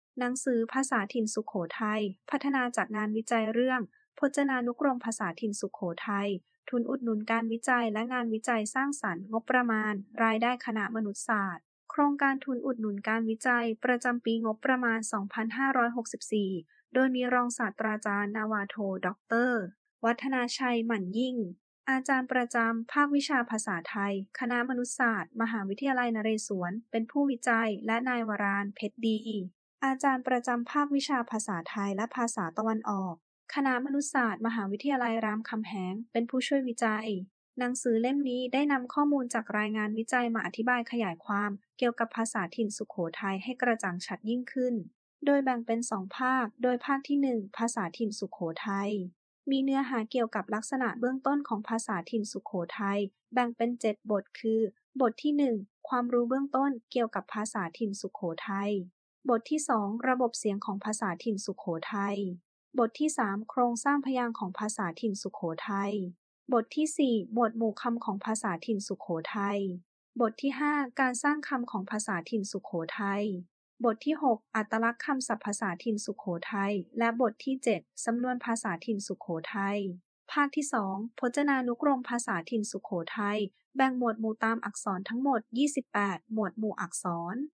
ภาษาถิ่นสุโขทัย
Sukhothai-dialect.mp3